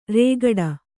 ♪ rēgaḍa